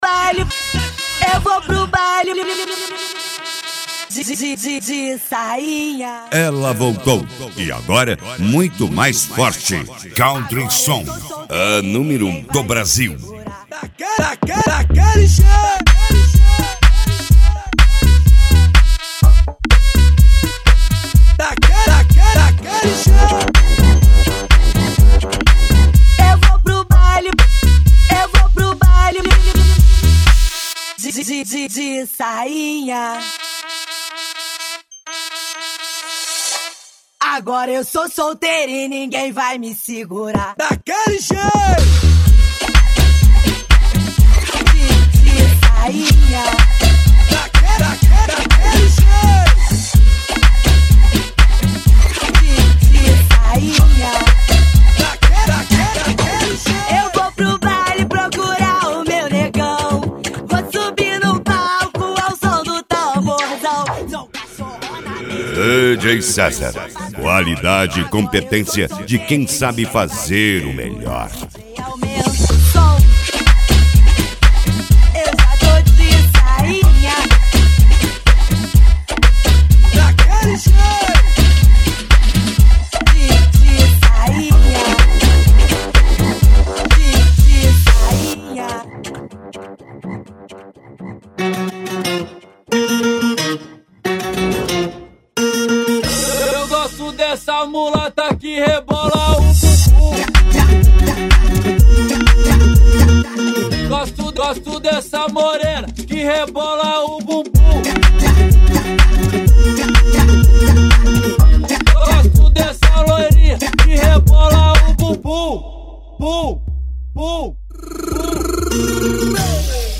Funk
Funk Nejo
Mega Funk